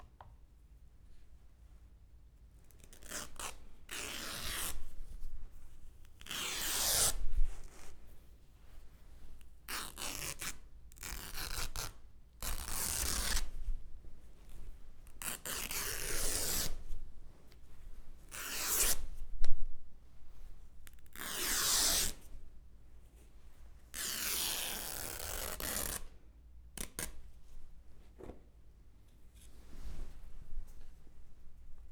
Teoskokonaisuuden ensimmäinen osa on kudonnainen, johon olen repinyt ja kutonut 20 tekemääni suurikokoista piirustusta, sekä äänittänyt repimisen ja kutomisen tapahtumat.